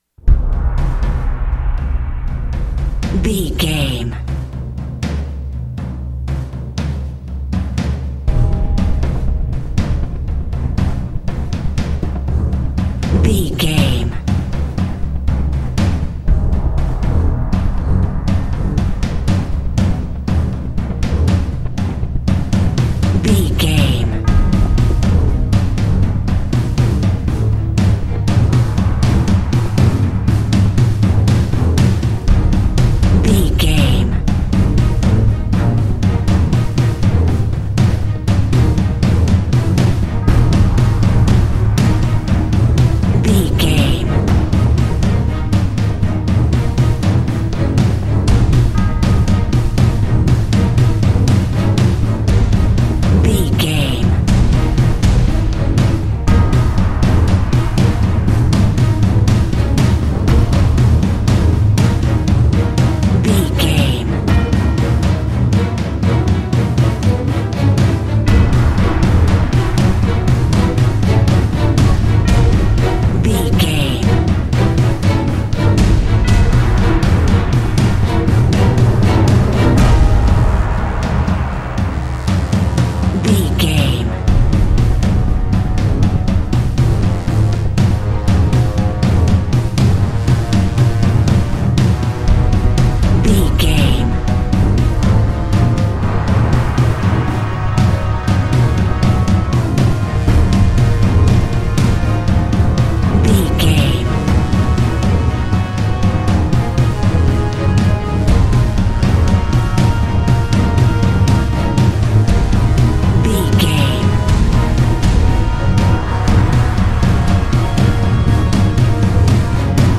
Epic / Action
Aeolian/Minor
E♭
strings
orchestral hybrid
dubstep
aggressive
energetic
intense
bass
synth effects
wobbles
driving drum beat
epic